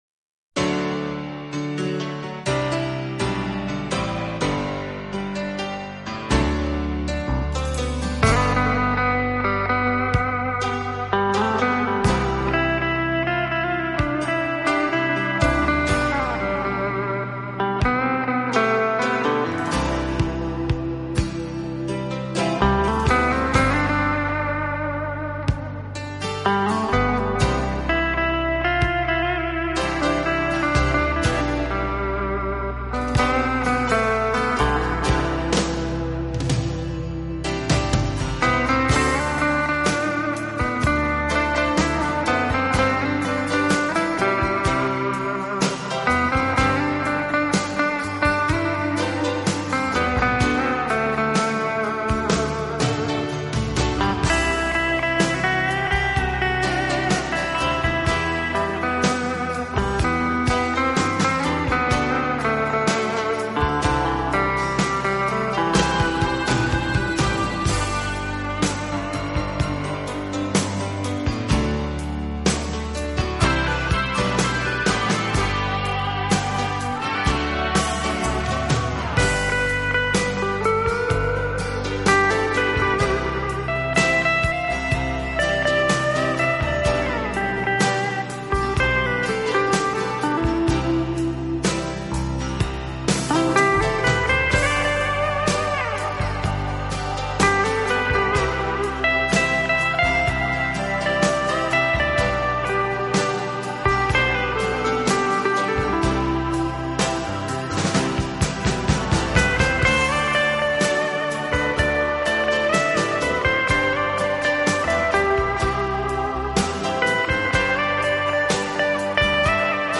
音乐类型：Soft Rock, Pop Rock, Classic Rock